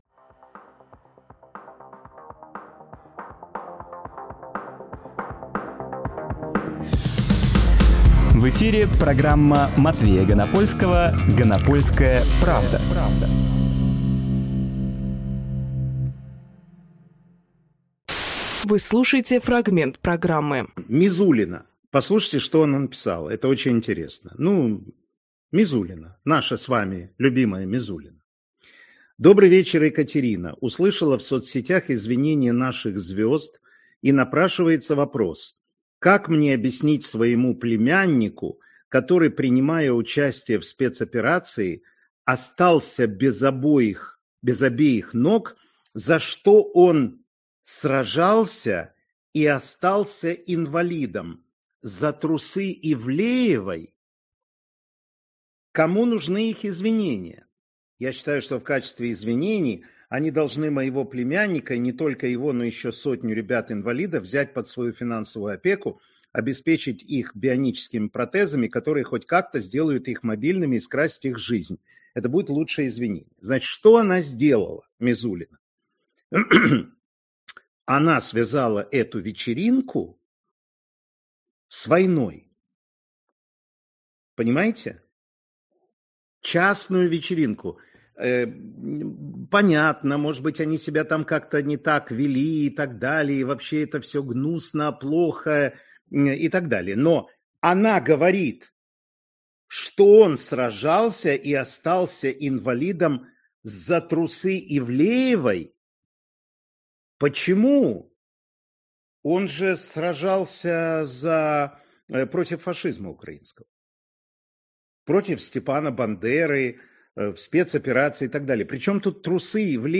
Фрагмент эфира от 28.12